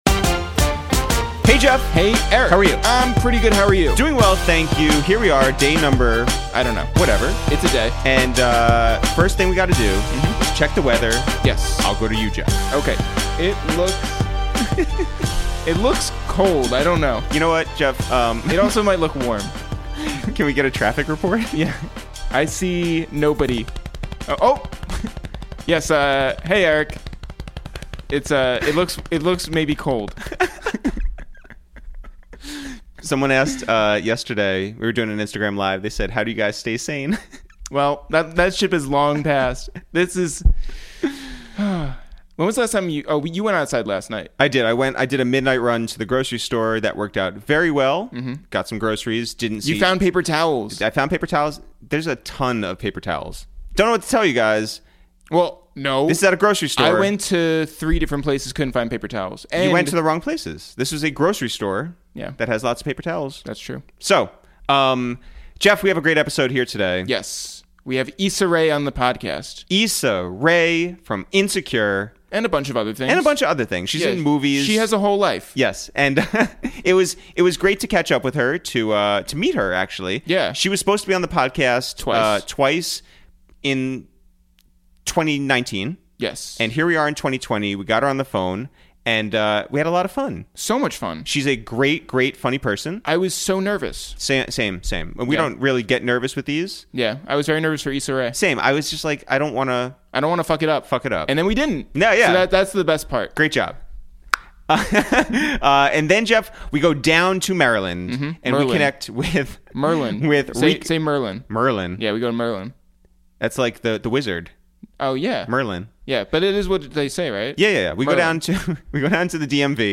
Today on Episode 28 of Quarantine Radio, we make calls from our Upper West Side apartment to check in on actor/writer/director/producer Issa Rae to talk about I-Wood, how she creates today as compared to the days of the 2008 recession, and who her personal Ned Ryerson is.